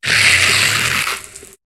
Cri de Solochi dans Pokémon HOME.